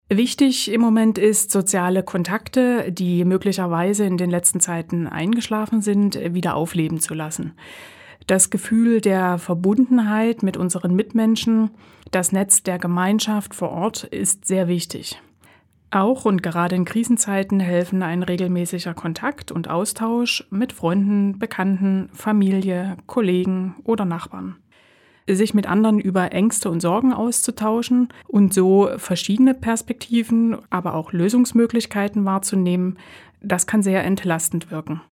O-Töne